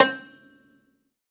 53v-pno14-G4.wav